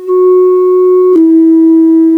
estimate of s(t), it sounds similar to the original, they are different because we don't hear the reverb during the second note, and also the 50 Hz frequency is not heard. It doesn't really sound like an organ anymore.